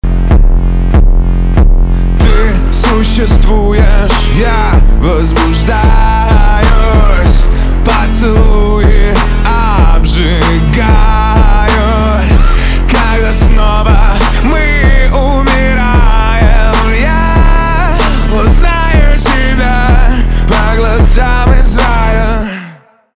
русский рок
гитара